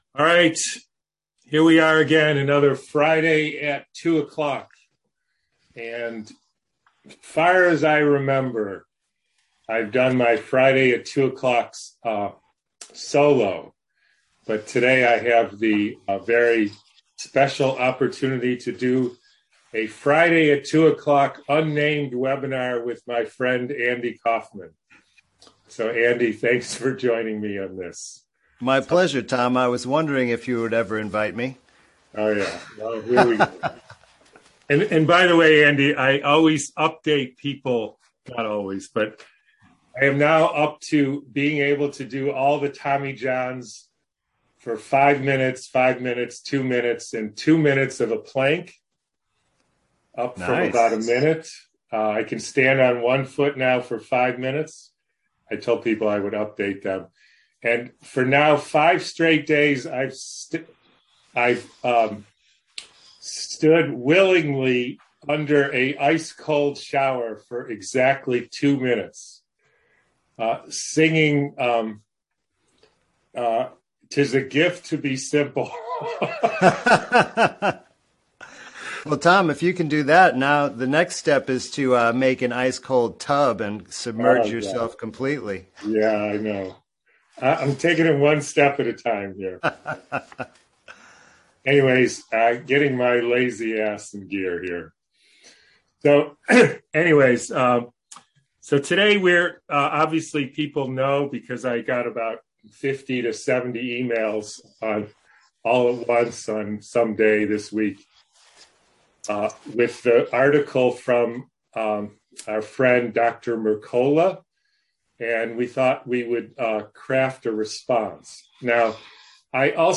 In this webinar